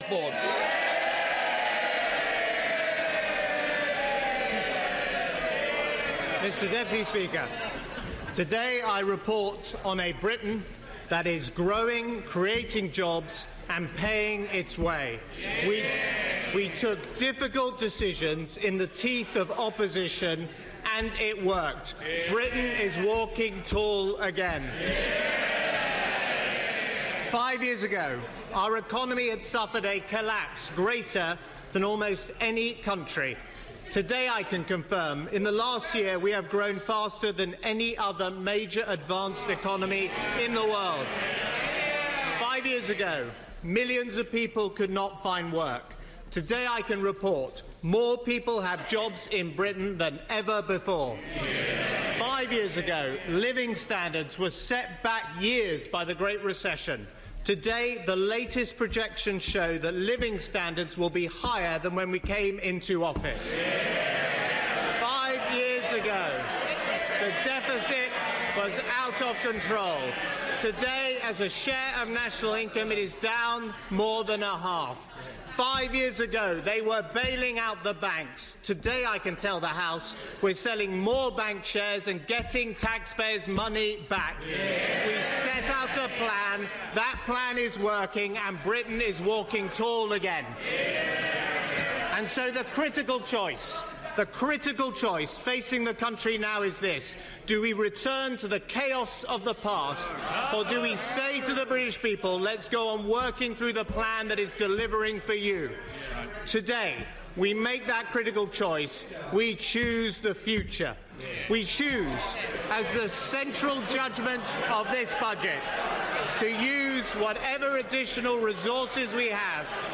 Budget 2015: George Osborne's full speech